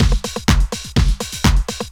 Event Beat 3_125.wav